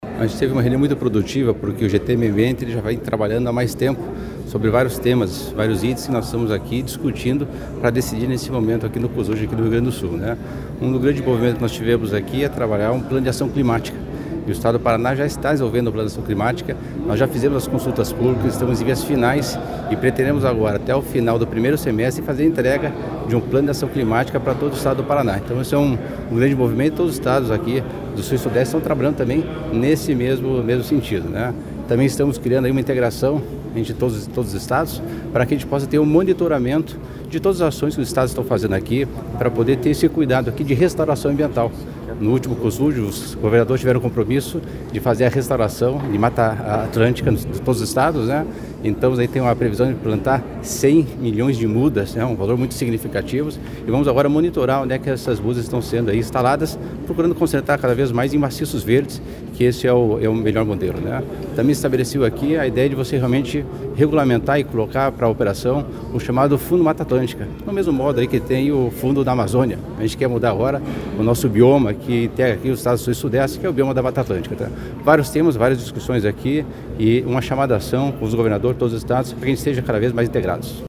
Sonora do secretário Estadual do Desenvolvimento Sustentável, Valdemar Bernardo Jorge, sobre os Grupos de Trabalho na reunião do Cosud, em Porto Alegre